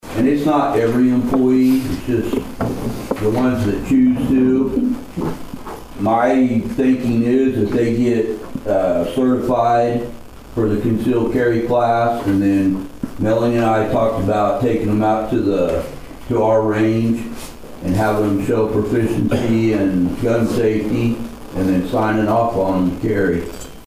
The Nowata City Commissioners held a regularly scheduled meeting on Monday evening at the Nowata Fire Department.
Police Chief Mike McElhaney discussed the process for the policy.